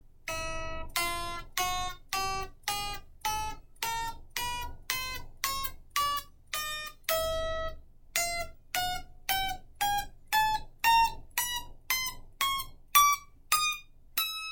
ギター音声 最初の次の音から、12音数えてみてください。 初めの音を含めて、ミの音が３回なっていますよね。